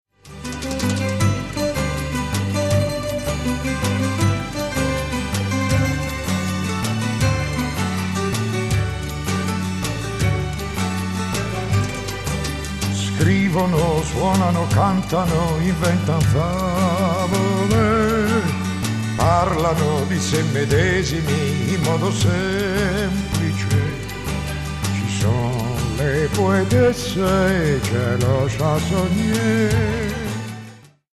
sonorità liriche e orchestrali
ud, darbuka, bandurria...